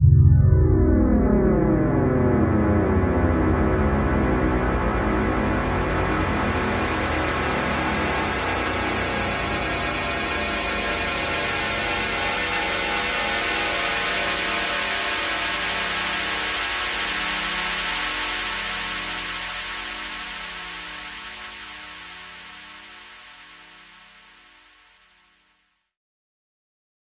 Index of /90_sSampleCDs/Club_Techno/Sweeps
Sweep_5_C2.wav